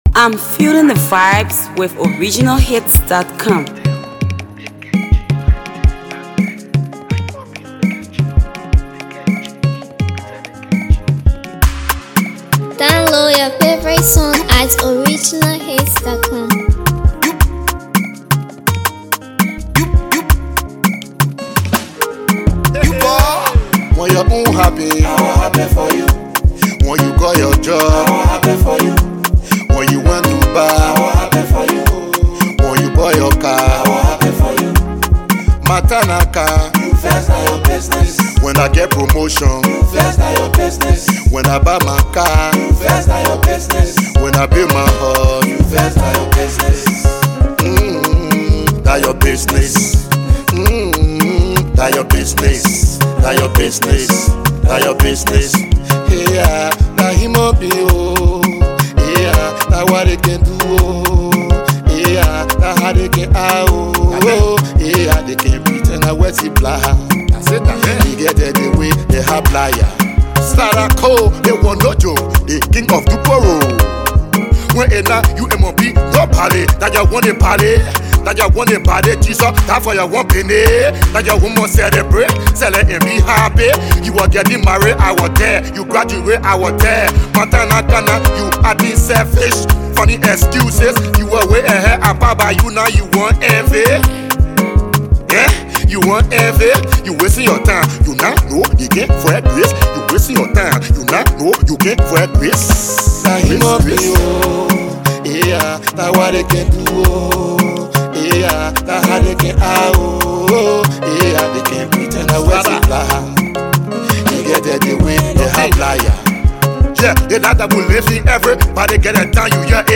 Hipco